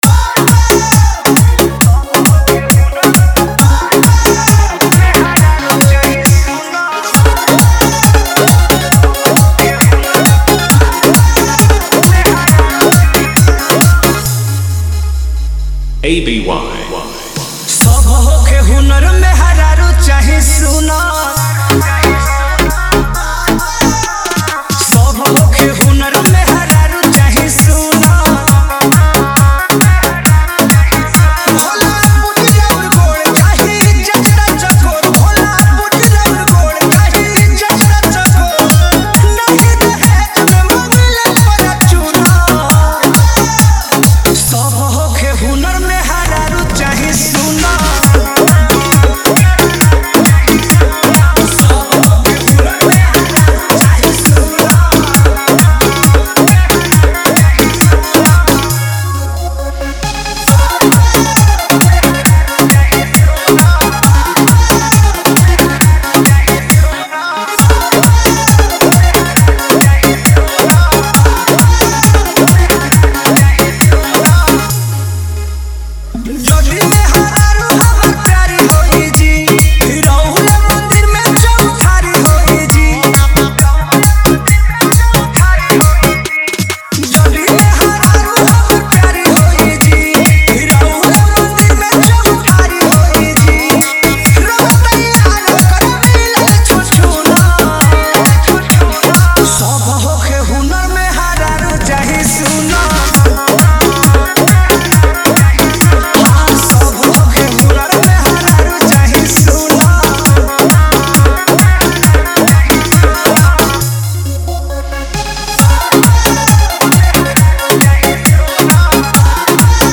Bolbam 2025 Dj Songs Report This Song Play Pause Vol + Vol -